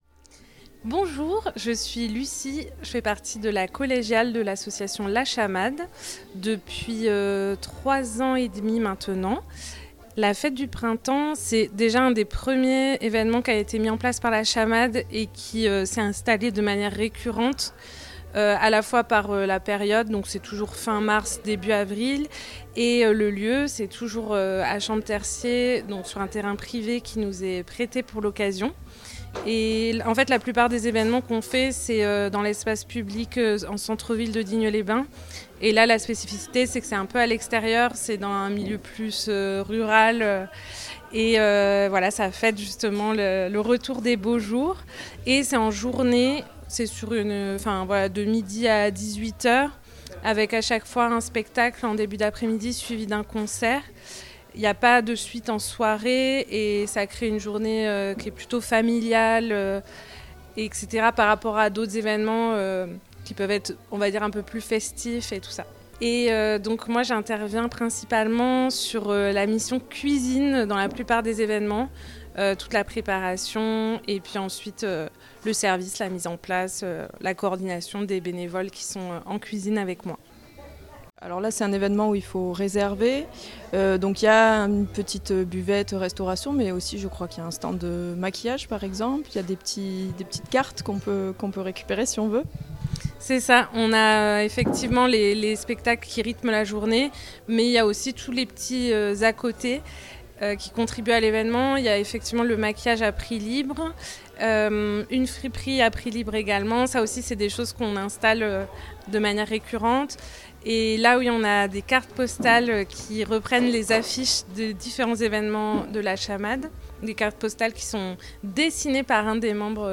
La Chamade - Fête du printemps 2025.mp3 (19.53 Mo)
En partenariat avec des producteur·rices locaux, la mairie de Champtercier, Provence Alpes Agglomération et des associations amies, La Chamade a offert une après-midi ensoleillée où se mêlaient assiettes printanières et spectacle vivant dans une ambiance inclusive et familiale.